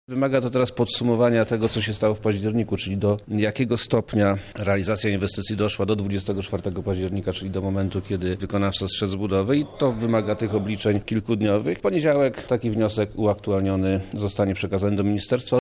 – mówi wojewoda lubelski Przemysław Czarnek